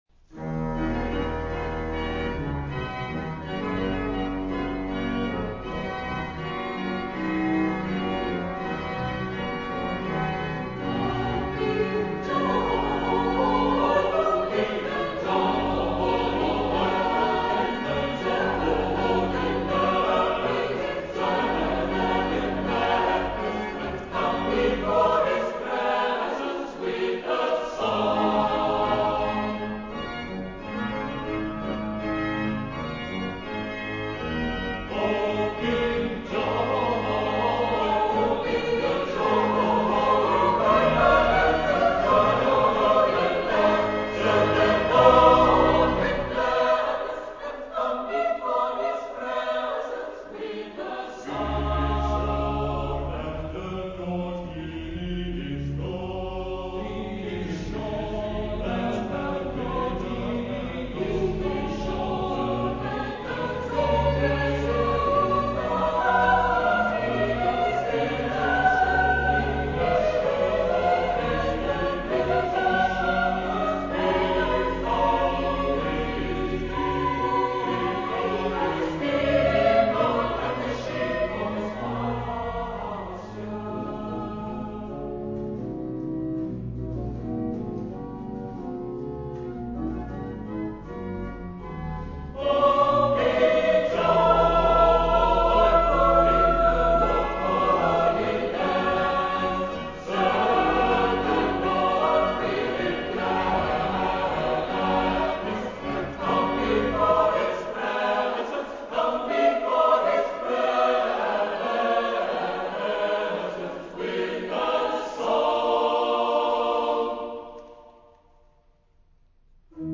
Klassiek (meer klassieke concerten)